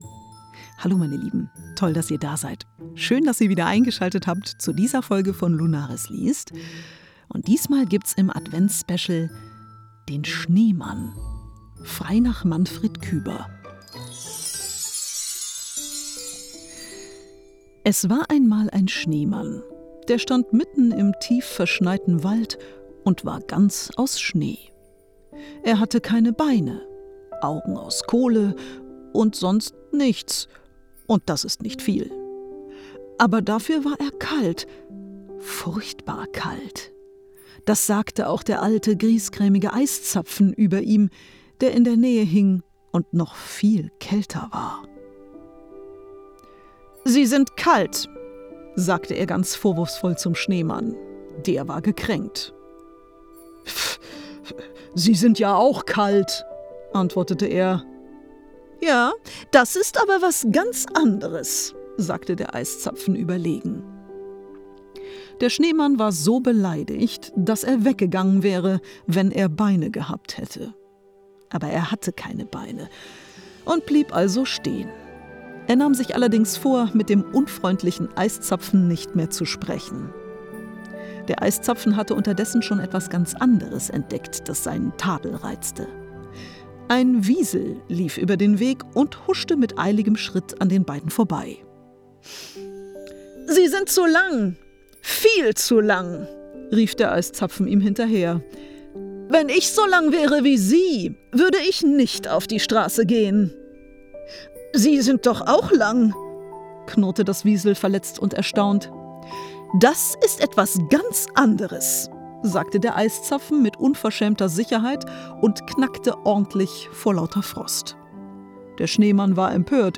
Hörbuch Weihnachten, Märchen zum Einschlafen, Advent,
Gute-Nacht-Geschichte